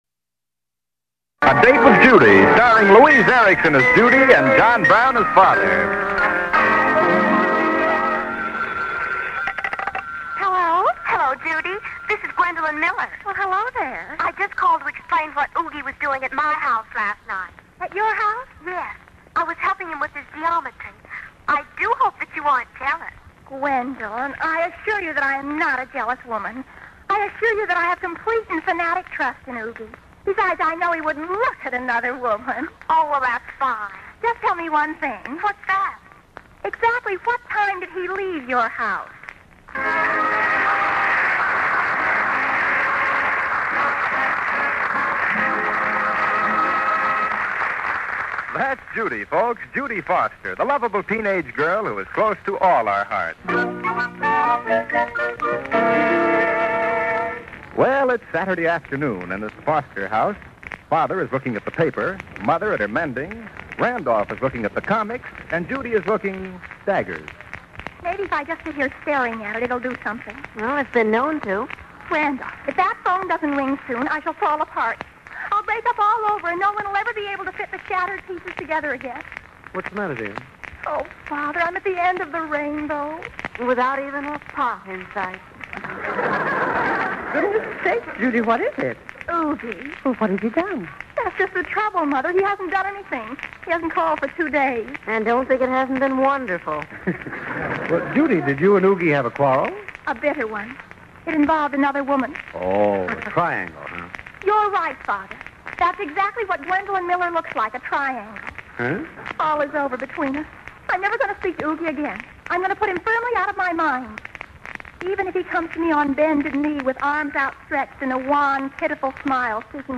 A Date with Judy Radio Program